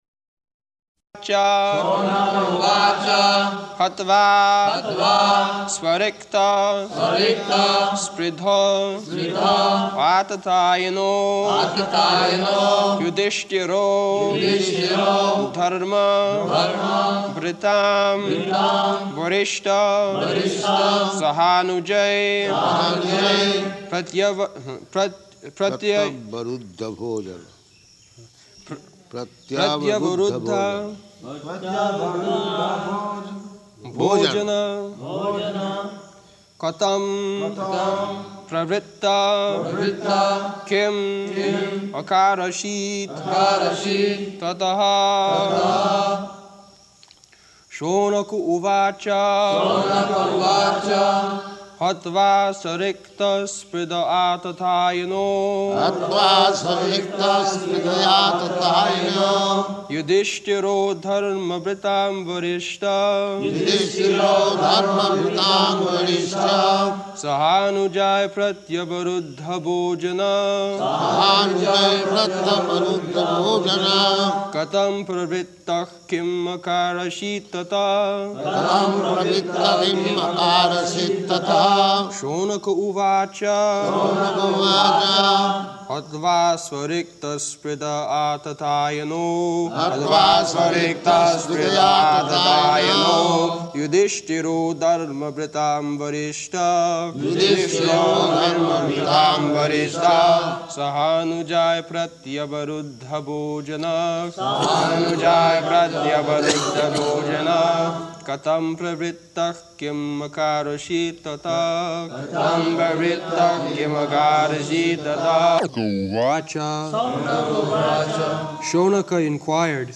Location: Māyāpur
[Prabhupāda interrupts to correct pronunciation of pratyavaruddha ]